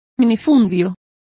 Complete with pronunciation of the translation of smallholding.